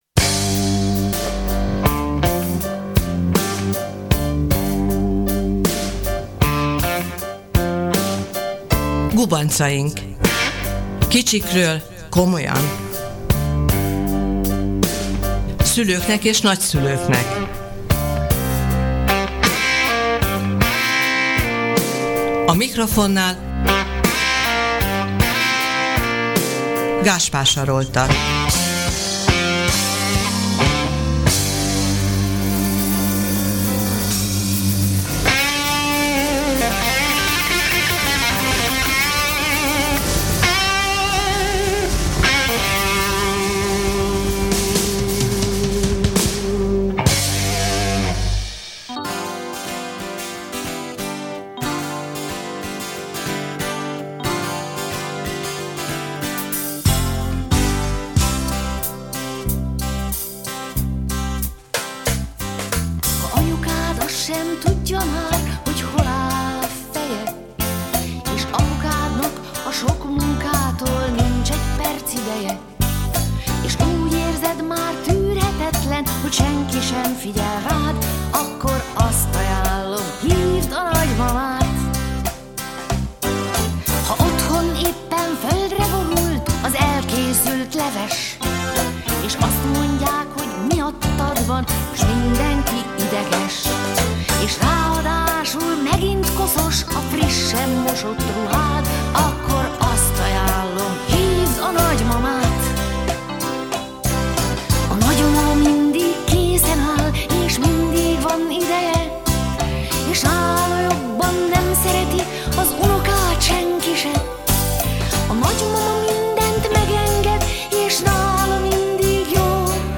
A Radió Bézs stúdiójában zajlott beszélgetésből mindezekre a kérdésekre választ kapunk.